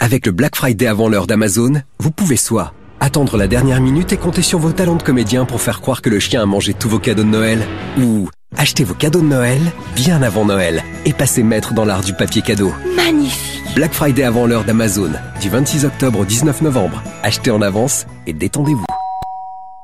2 spots actuellement diffusés sur toutes les radios françaises